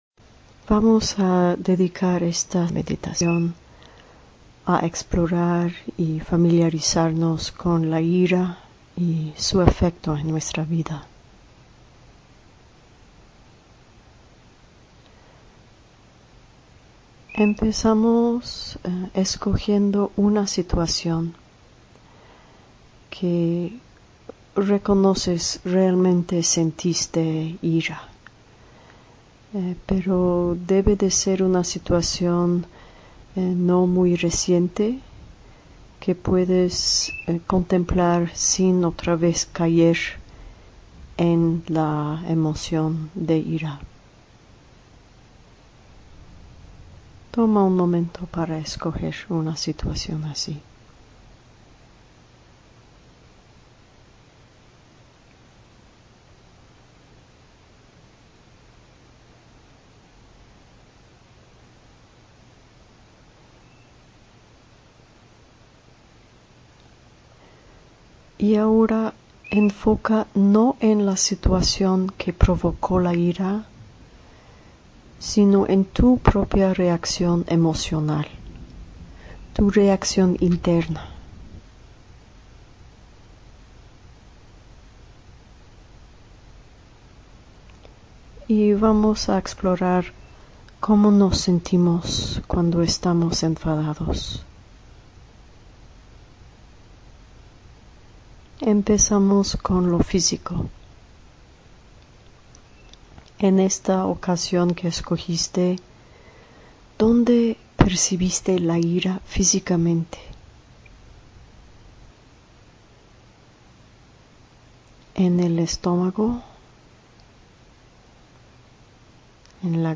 Meditación tema 2
Meditacion sobre la ira.mp3